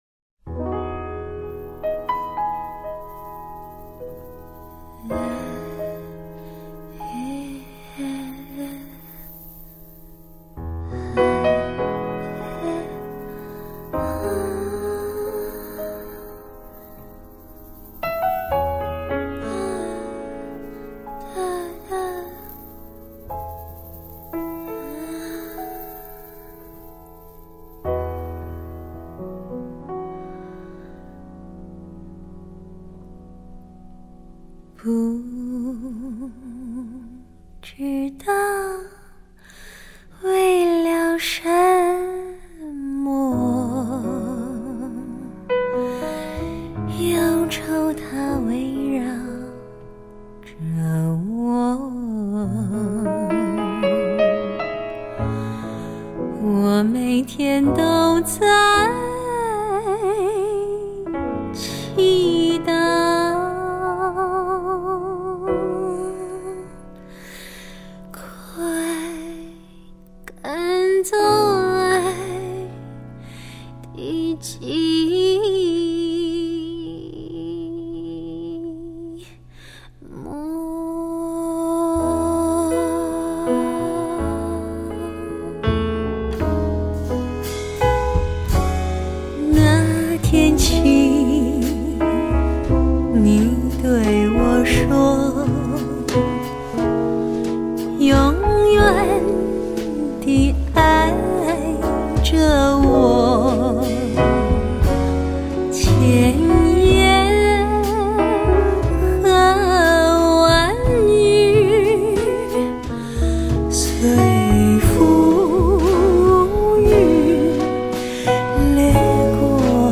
[华语发烧]
这群来自四面八方的乐手凑在一起演奏，配合得默契自然，所有歌曲的音乐部分以现场（Live）的方式收录。
新专辑不仅把她的原有特色发挥尽致，还显示出她对自身嗓音的高超控制能力。
这张经过24比特HDCD技术处理的唱片，肯定是今年上市的国产录音中优秀的女声天碟之一。